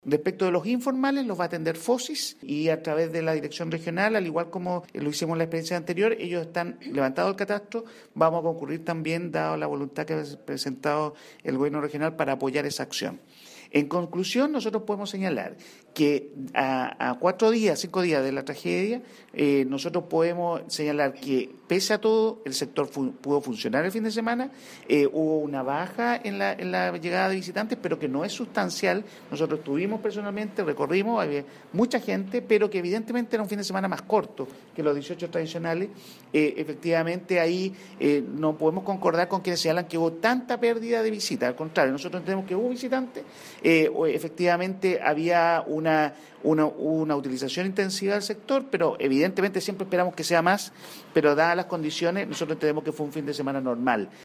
Y en cuanto a la evaluación general, el seremi de Economía señaló que el comercio de Concón funcionó durante el fin de semana, sin embargo, reconoció una baja en los visitantes. Escuche las declaraciones de Omar Morales.